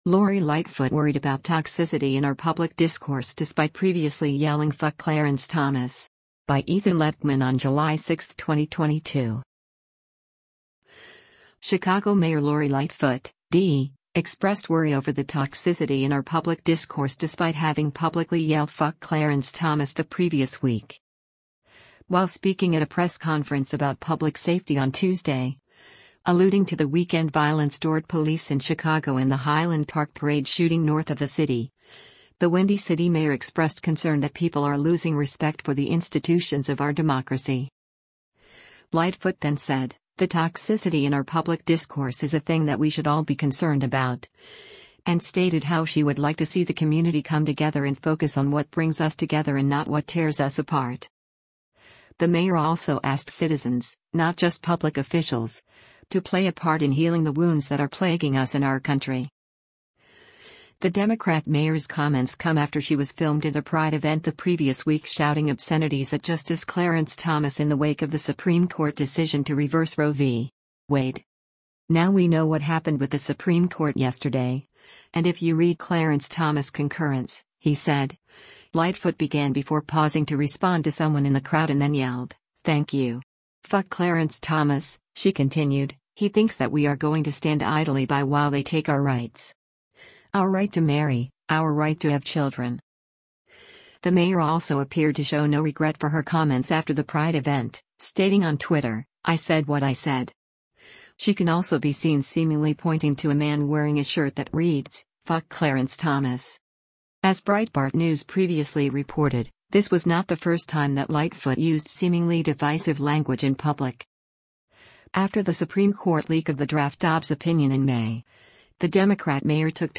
While speaking at a press conference about public safety on Tuesday, alluding to the weekend violence toward police in Chicago and the Highland Park parade shooting north of the city, the Windy City mayor expressed concern that “people are losing respect for the institutions of our democracy.”